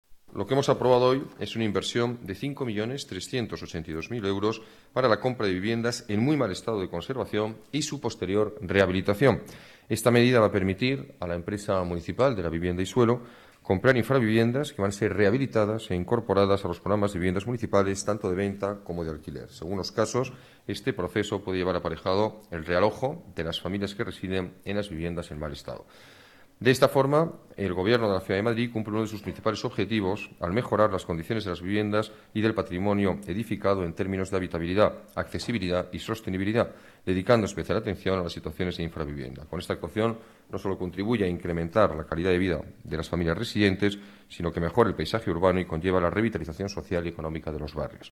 Nueva ventana:Alcalde en rueda de prensa, sobre rehabilitación de viviendas